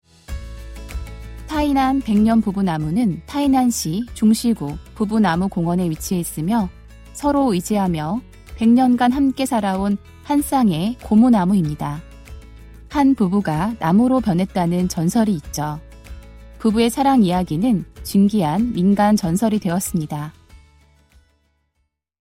한국어 음성 안내